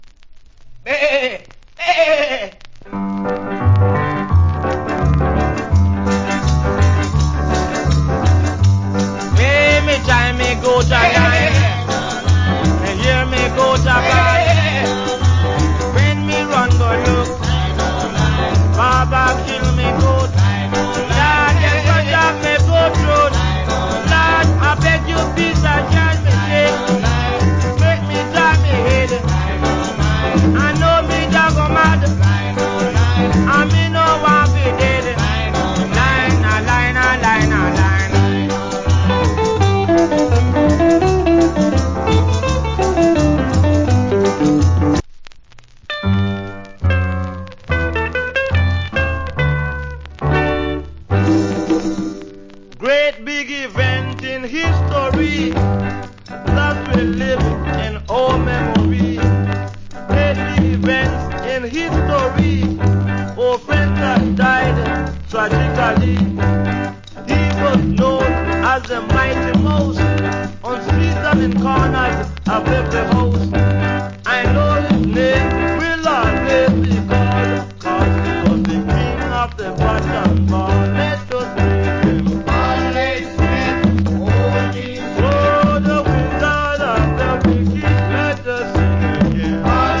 Wicked Calypso Vocal.